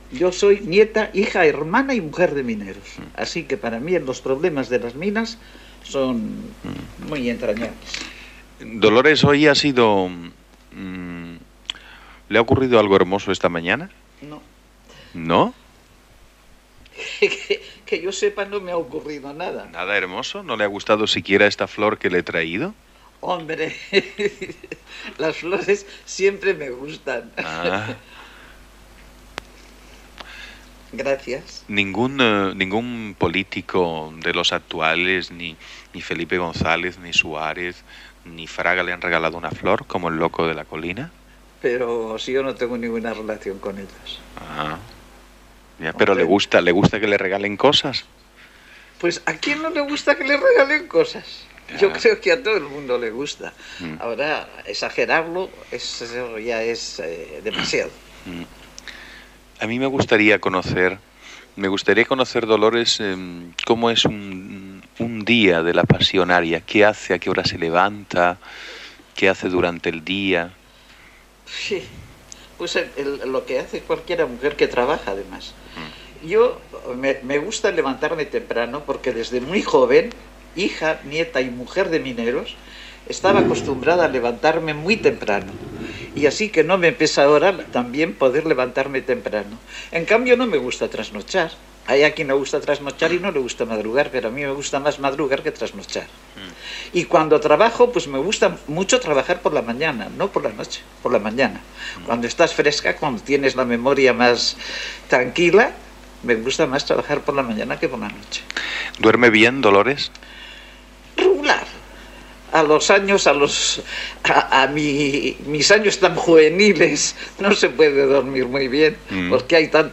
Fragment d'una entrevista a Dolores Ibárruri, La Pasionaria.